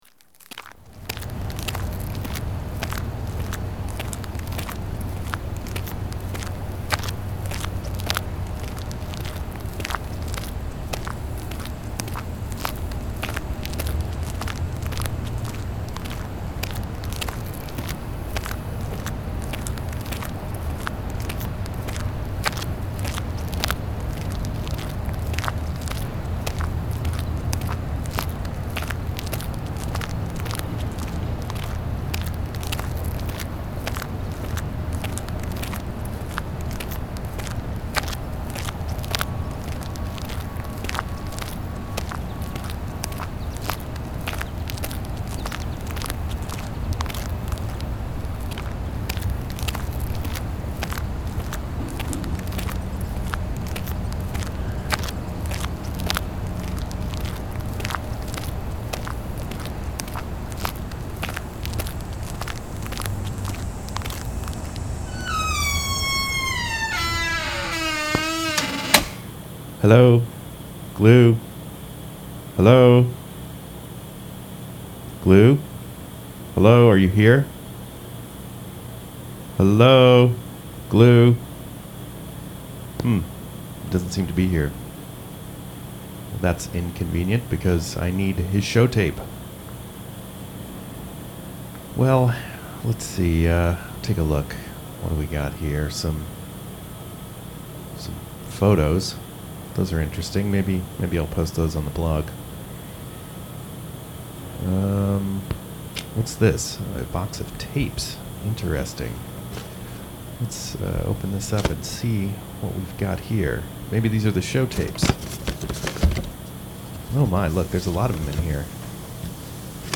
Transmission Arts & Experimental Sounds